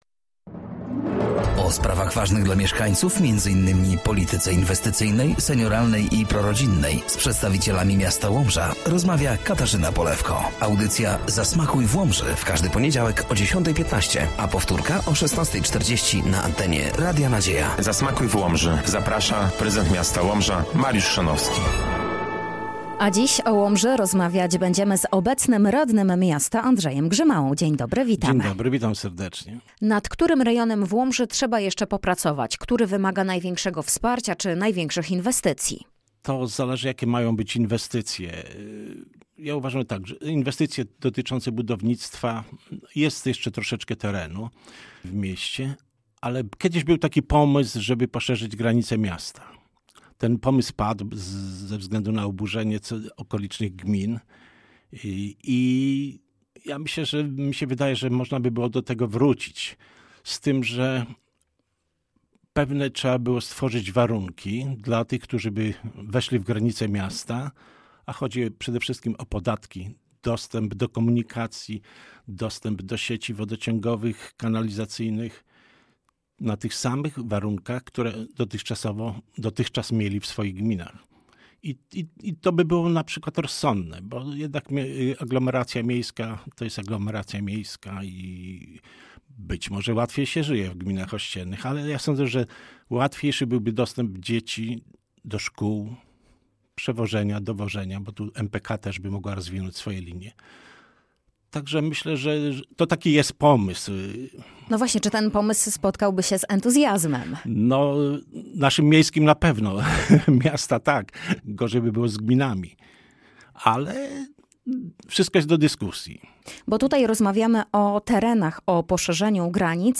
Audycja „Zasmakuj w Łomży”, w każdy poniedziałek o 10.15 na antenie Radia Nadzieja.
Gościem siódmej audycji był Andrzej Grzymała – Radny Rady Miasta Łomża.